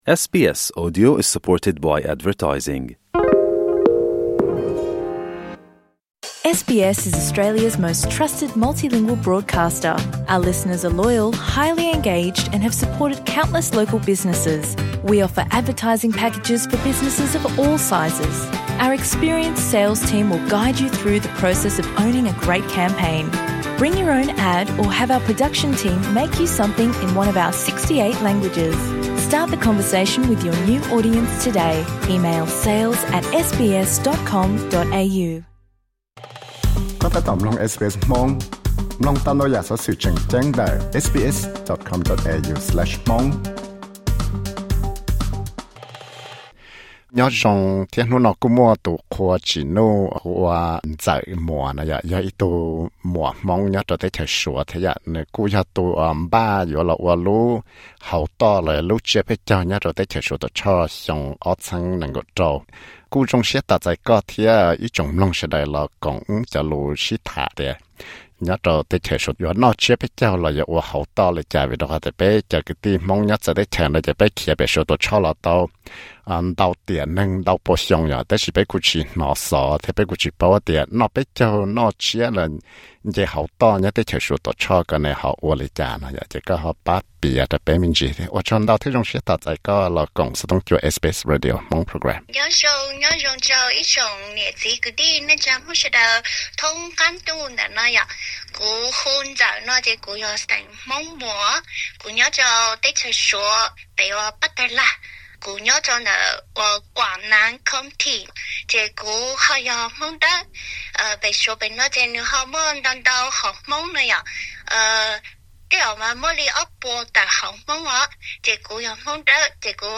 Interview: Hmoob Suav lub hauv toj 2026